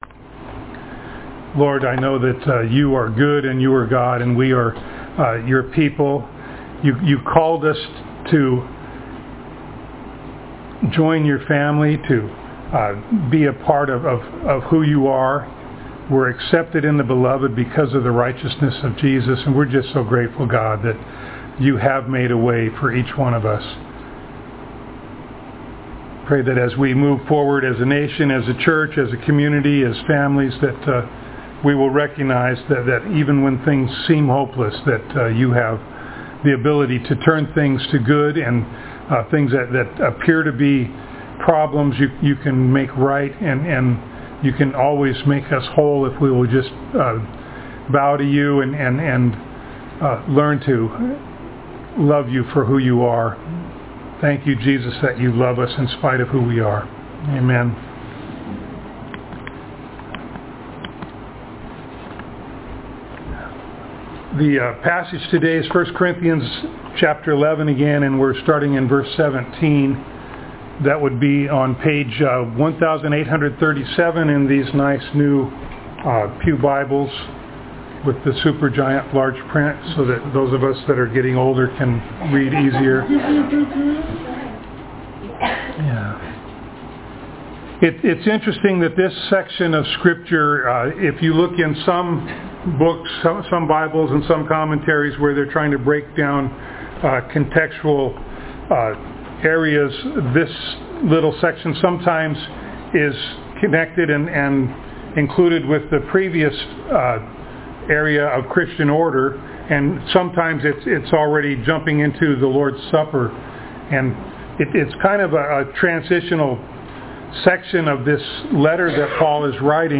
1 Corinthians Passage: 1 Corinthians 11:17-22, 1 Corinthians 1:4-9, 1 Corinthians 11:2, Galatians 2: 11-14, Galatians 5:13-15 Service Type: Sunday Morning Download Files Notes « Huh!